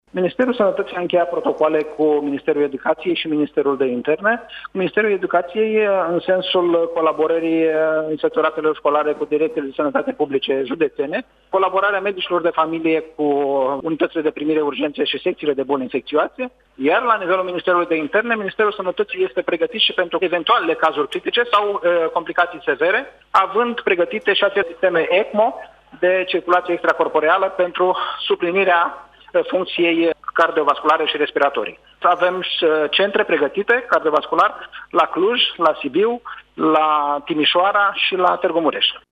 Nelu Tătaru, Secretar de stat în Ministerul Sănătății, pentru Digi24.